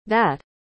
¿Cómo se pronuncia That correctamente?
La palabra that se pronuncia /ðæt/, y sí, ese sonido /ð/ es el típico que no tenemos en español.